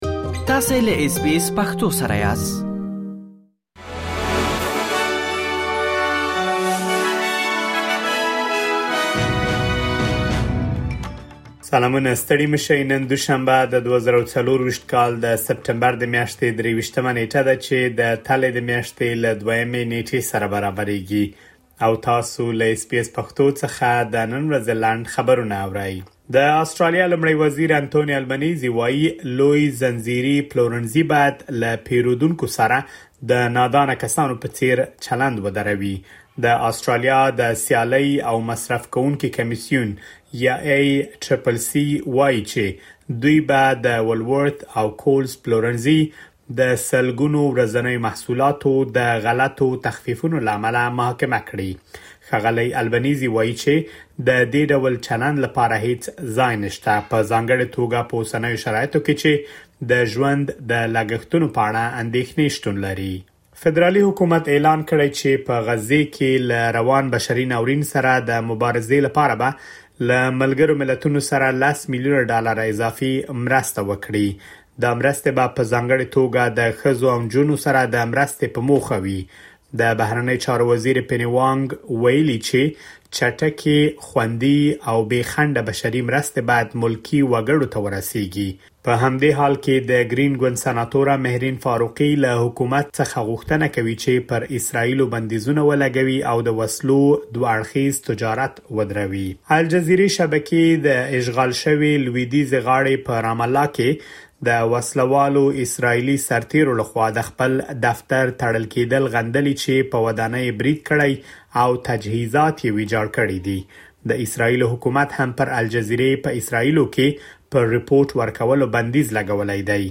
د اس بي اس پښتو د نن ورځې لنډ خبرونه|۲۳ سپټمبر ۲۰۲۴